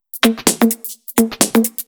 Index of /VEE/VEE2 Loops 128BPM
VEE2 Electro Loop 127.wav